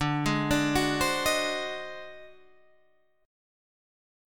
D 7th Flat 9th Flat 5th